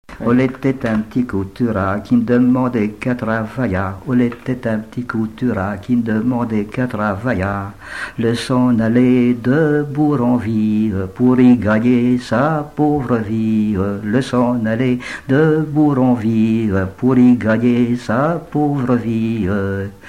airs au cornet à piston
Pièce musicale inédite